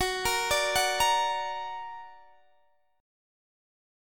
Listen to Gb+ strummed